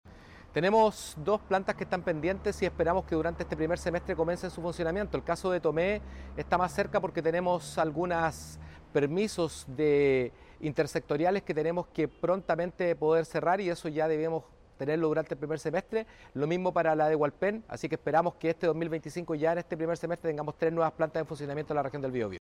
El titular de la cartera regional del Ministerio de Transportes comentó la puesta en marcha de otras Plantas de Revisión Técnica, explicando puntualmente a los casos de Tomé y Hualpén.